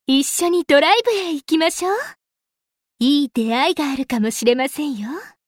语音语音排序为：中文-日文